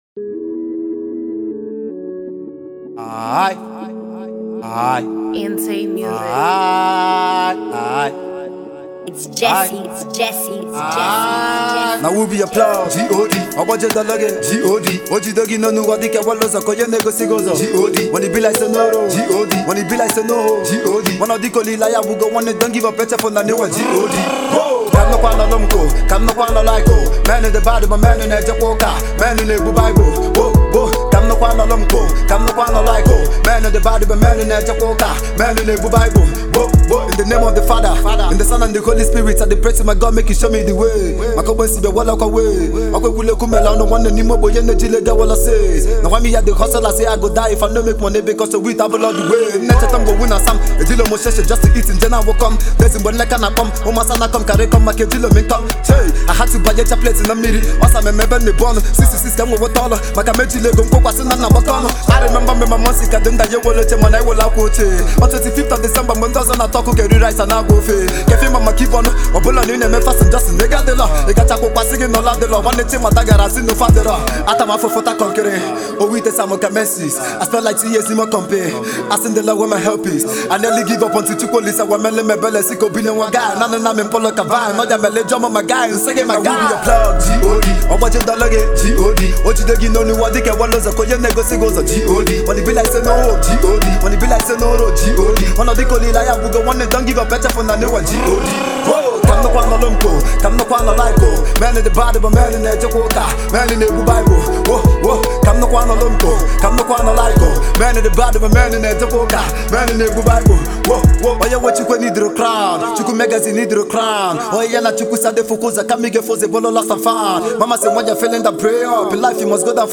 T.R.A.P-infused tracks